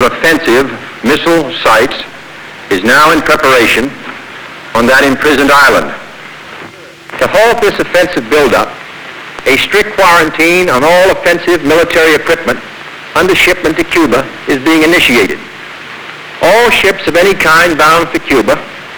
宣言を読み上げるケネディは、ある時は切々と、ある時は断固たる意志を持って語り掛けた、日本のケネディ暗殺事件研究者の一人でありドキュメンタリー作家の落合信彦はその著書のなかでこう書いている。